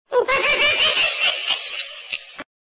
goofy monkey laugh sound effect sound effects free download
goofy monkey laugh sound effect sped up